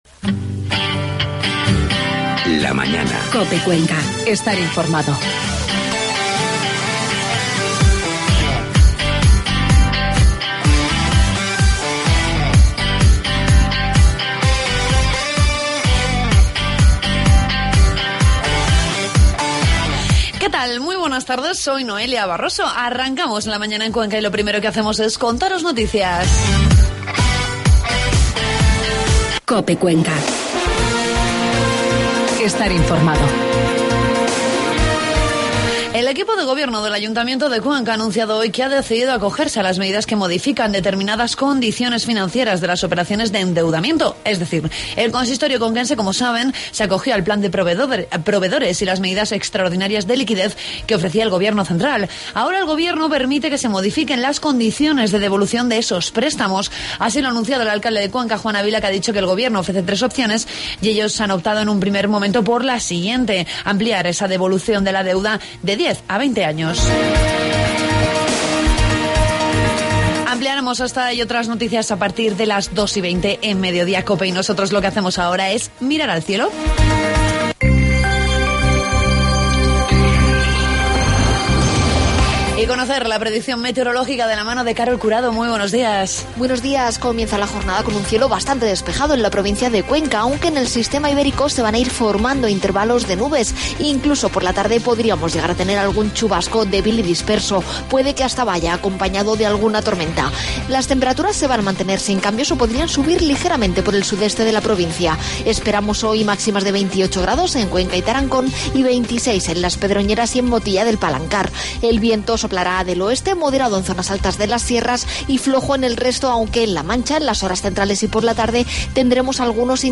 Entrevistamos al alcalde de Villar de Cañas, José María Saiz.